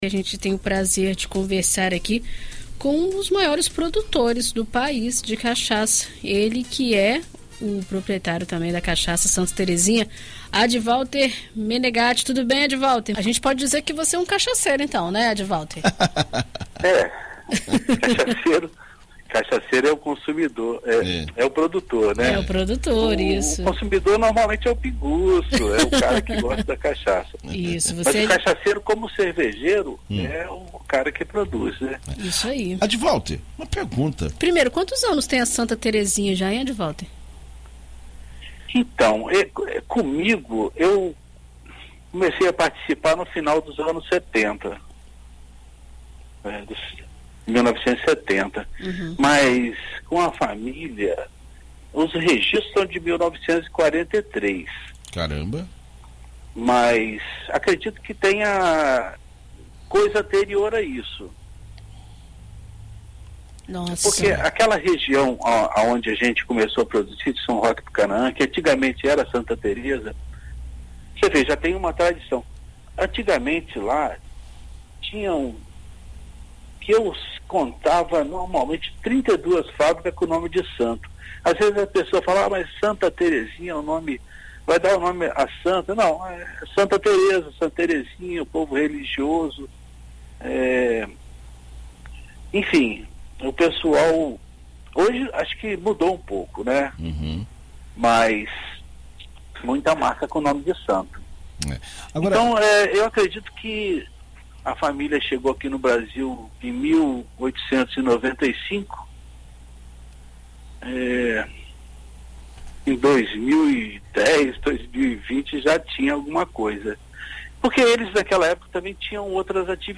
Em entrevista à BandNews FM Espírito Santo nesta terça-feira (13)